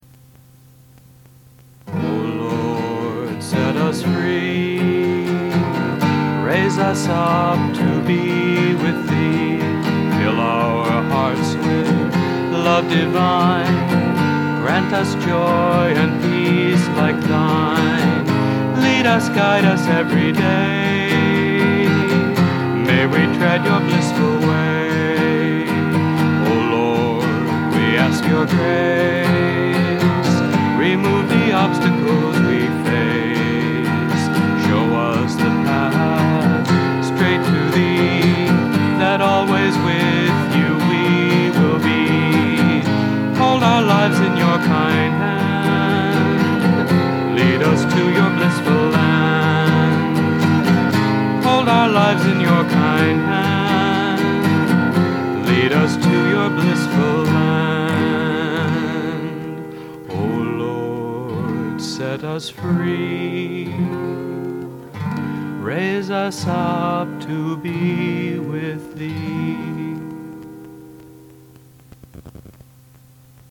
1. Devotional Songs
Major (Shankarabharanam / Bilawal)
8 Beat / Keherwa / Adi
4 Pancham / F
1 Pancham / C